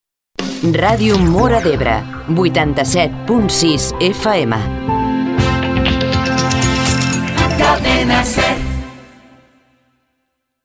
Identificació i freqüència